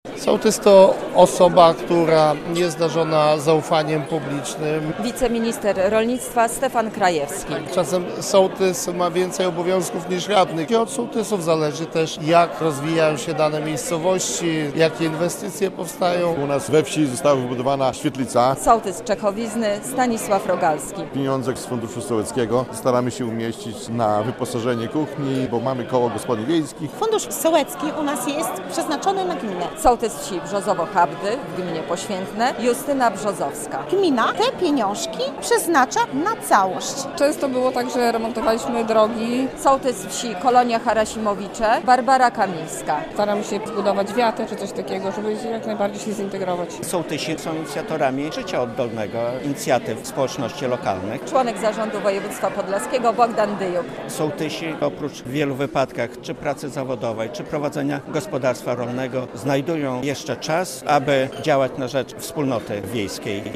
Spotkanie z okazji Dnia Sołtysa - relacja